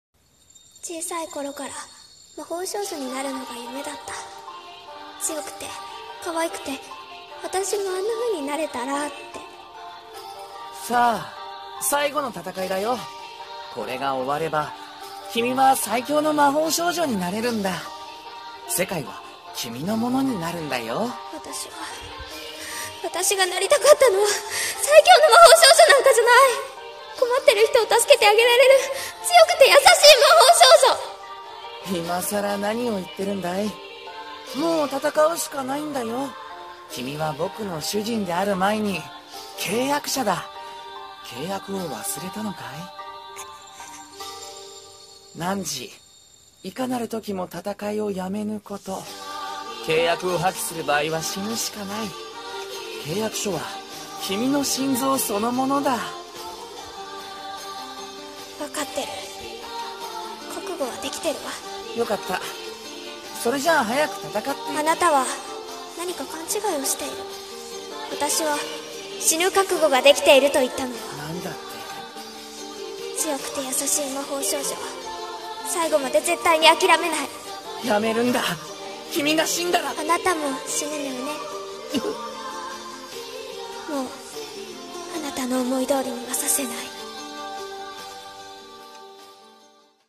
声劇台本【魔法少女の最期の戦い】